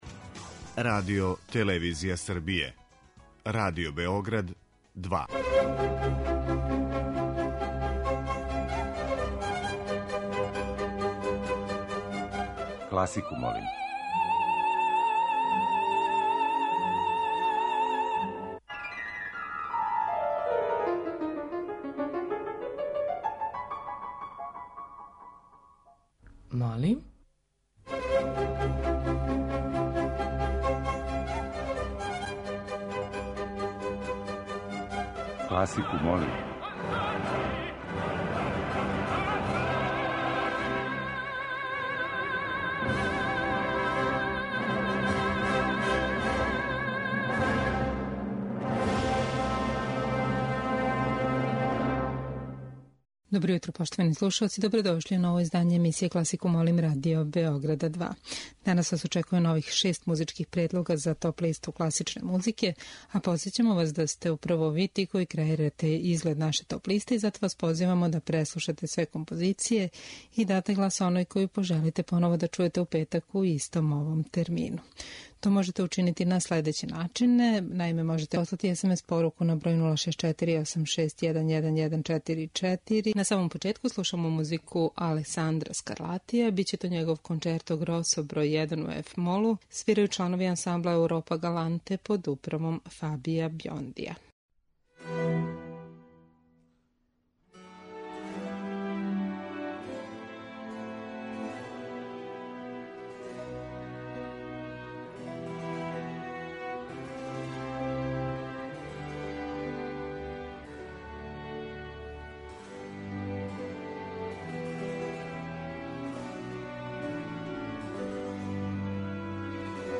Избор за недељну топ-листу класичне музике РБ2